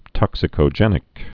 (tŏksĭ-kō-jĕnĭk)